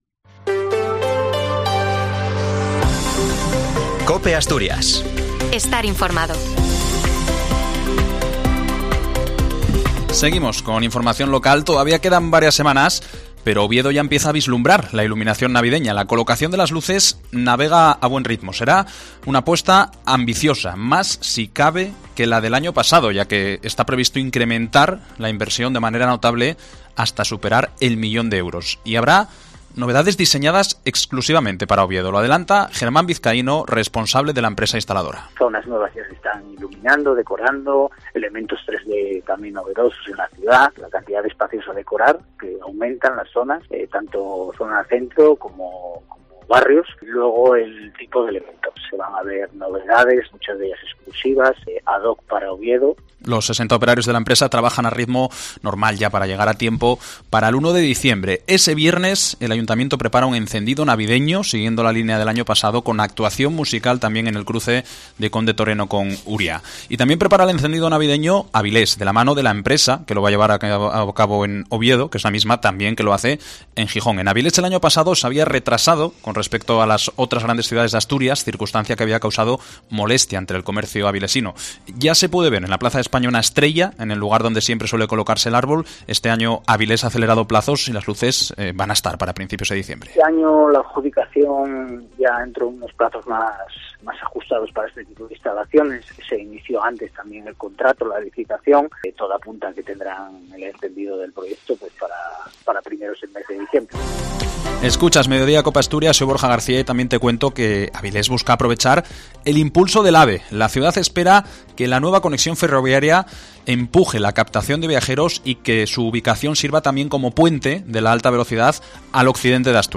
Declaraciones de Barbón y Mañueco tras las palabras de Gallardo